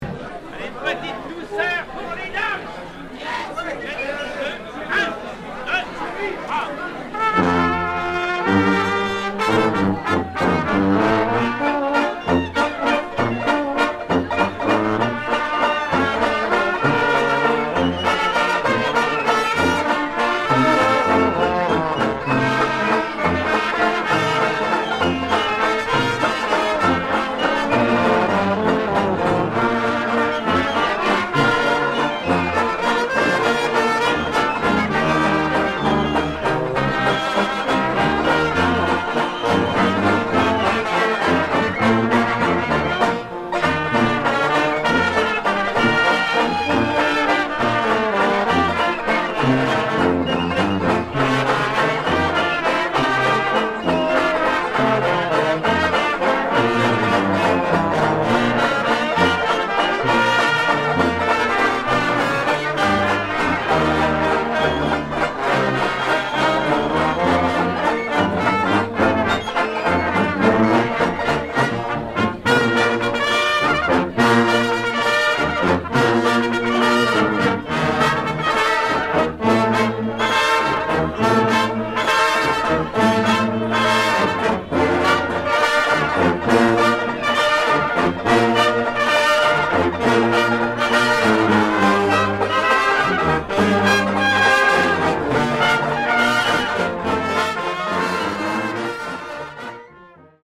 Fanfare Octave Callot des Beaux Arts et autres lieux… Accueil Ragtimes, valses & bostons Sobre las olas Sobre las olas Juventino Rosas Écoutez Sobre las olas de Juventino Rosas interpreté par la Fanfare Octave Callot Téléchargez le morceau ← I'm forever blowing bubbles ↑ Ragtimes, valses & bostons Calliope Rag →